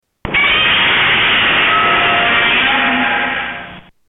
Godzilla Roar - 1970s